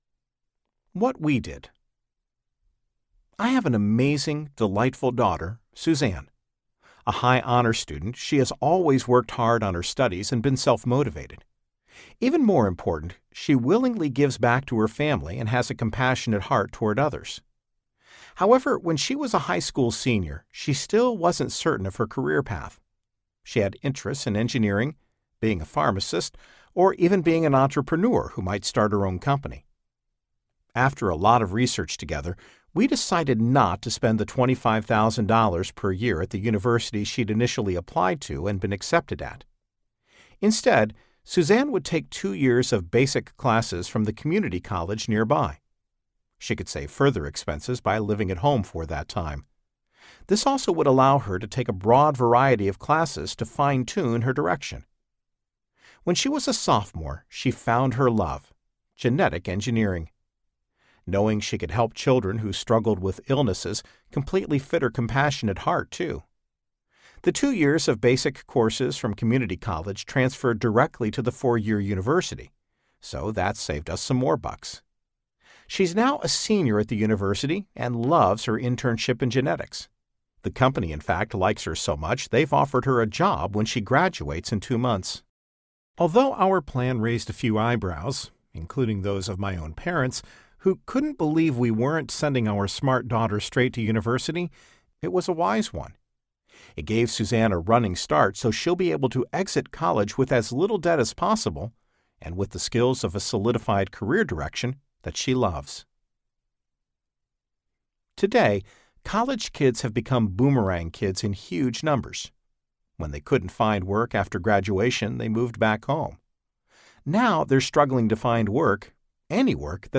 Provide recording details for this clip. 6.67 Hrs. – Unabridged